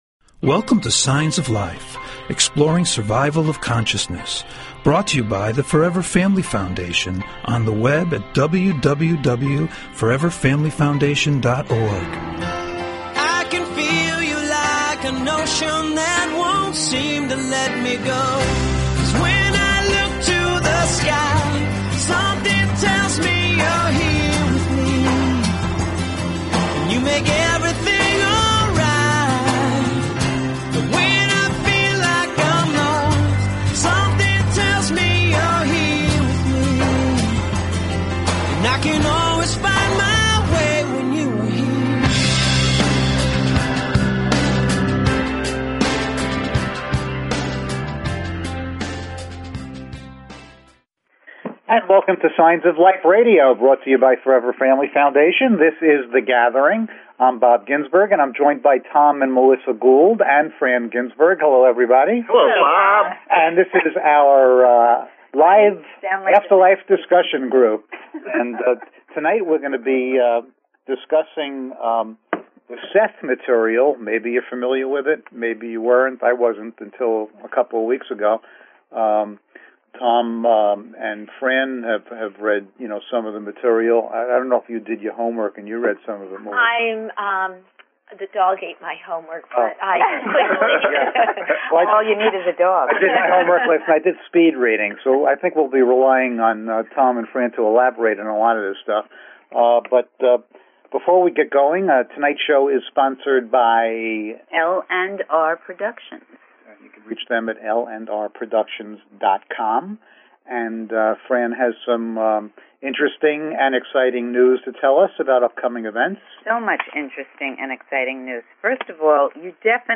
SHORT DESCRIPTION - The Gathering - Discussion show with listener call-ins. Topic - The Seth Material